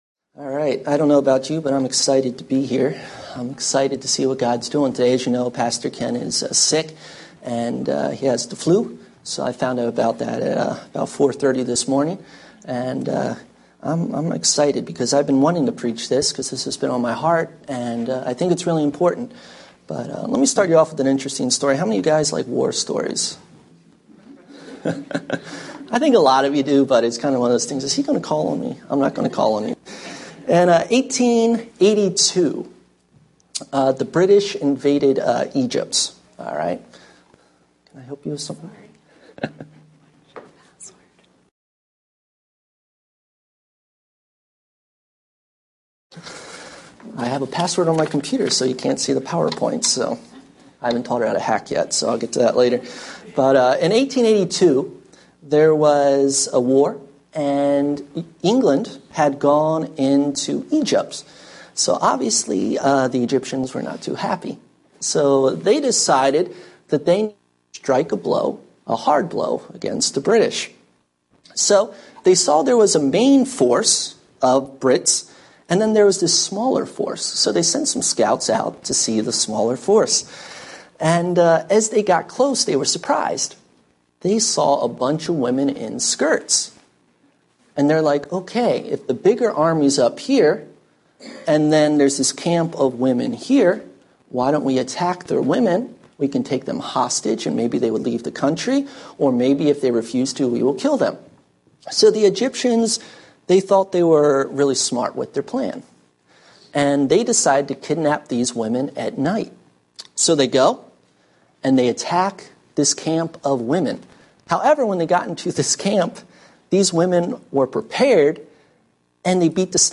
Sermons Archive - Page 15 of 27 - Colmar Manor Bible Church - Maryland